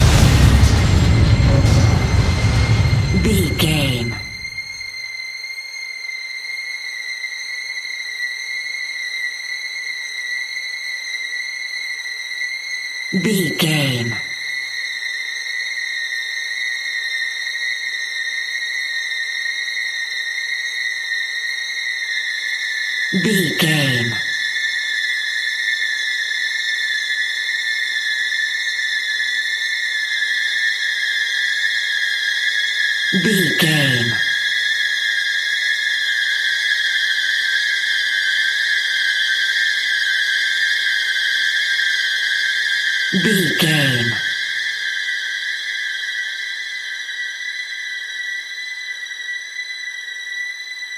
Sound Effects
Atonal
tension
ominous
eerie